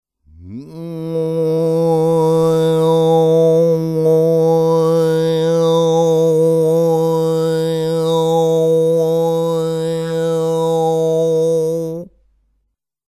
Chant diphonique à 1 cavité buccale
chant diphonique
Pays Mongolie